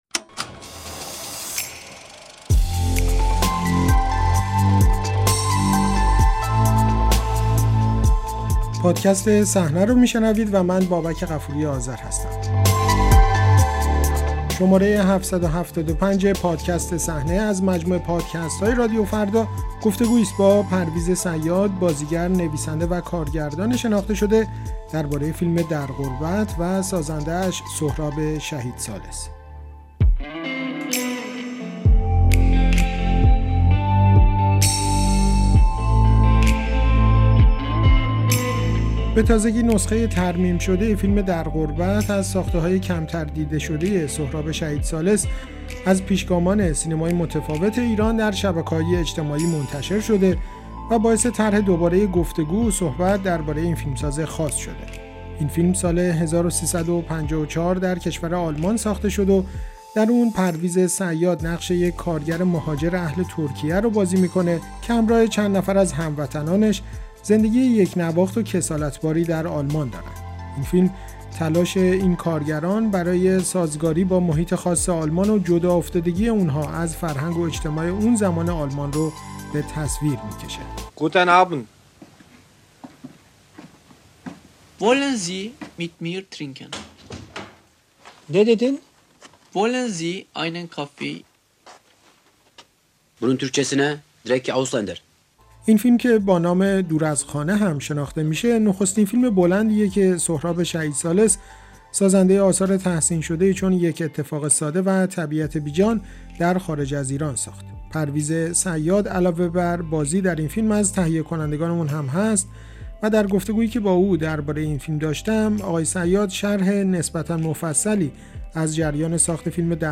بازخوانی «در غربت» و سهراب شهیدثالث در گفت‌وگو با پرویز صیاد